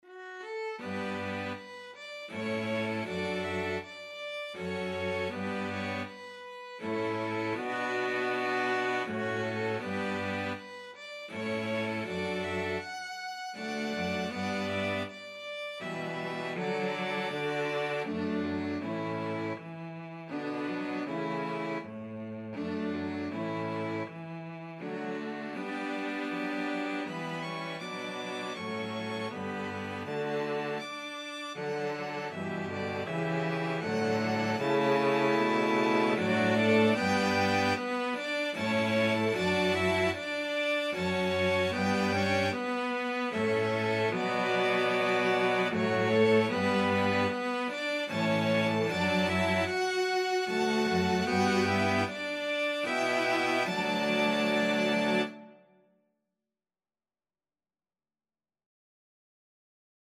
Violin
CelloViola
Cello
Andante
3/4 (View more 3/4 Music)